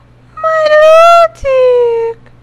Cries
MILOTIC.mp3